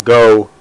Go Sound Effect